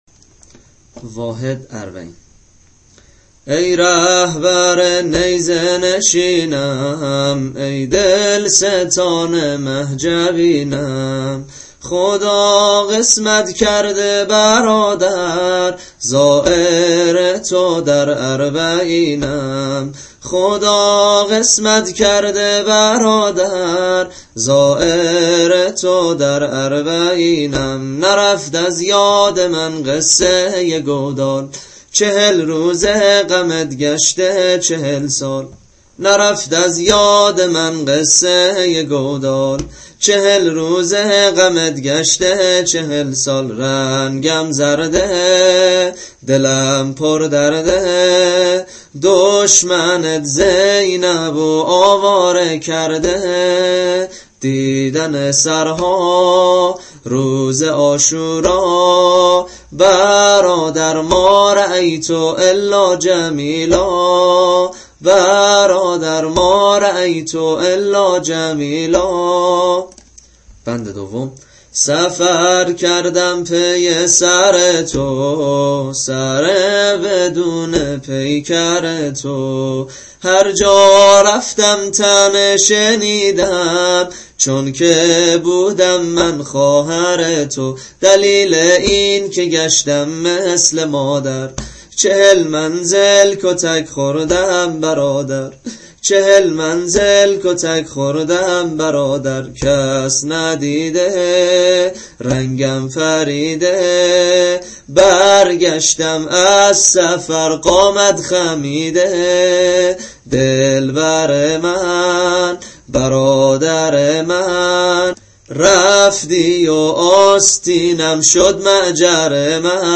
به همراه سبک نوحه